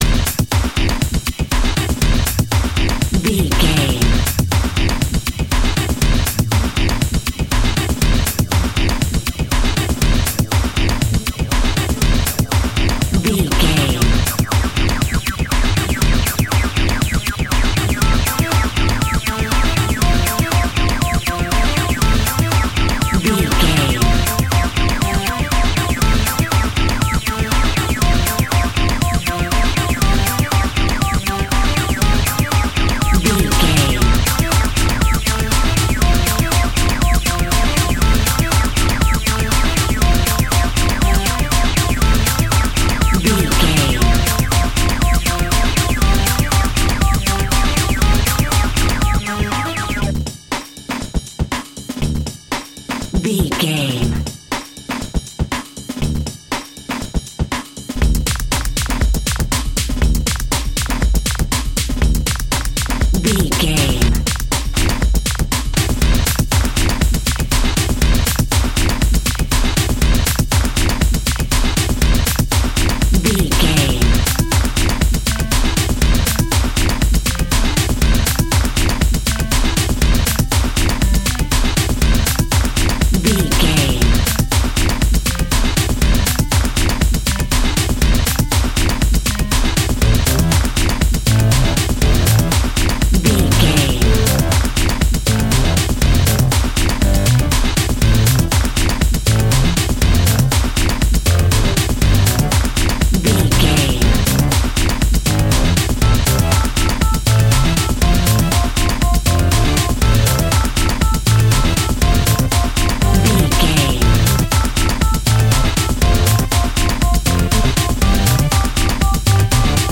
Powerful Sports Music.
Epic / Action
Fast paced
Aeolian/Minor
intense
energetic
driving
dark
strings
drums
drum machine
synthesiser
electronic
techno
trance
industrial
glitch